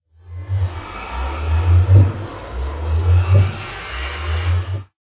portal.ogg